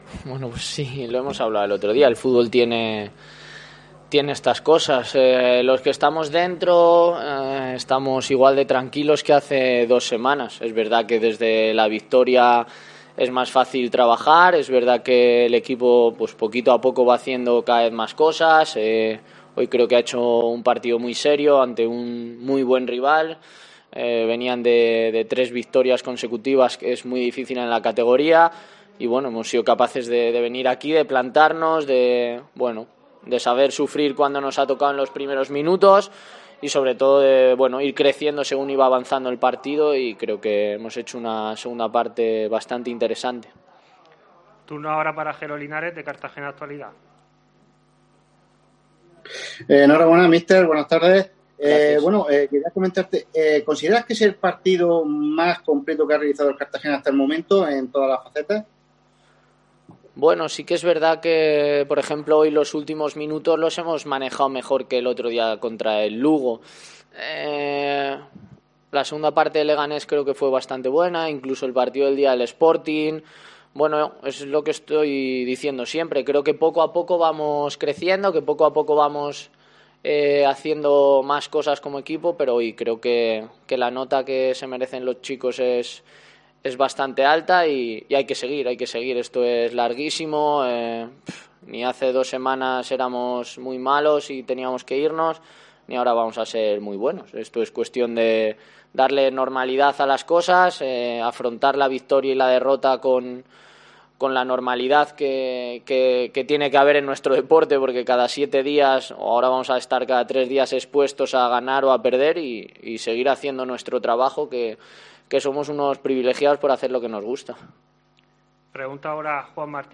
AUDIO: Escucha aquí las palabras el entrenador del Cartagena tras ganar en El Toralín 1-0 a la Deportiva Ponferradina